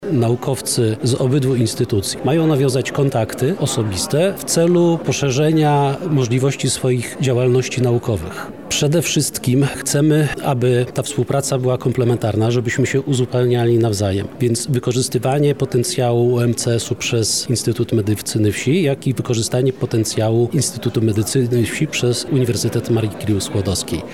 Ecotech-wywiad.mp3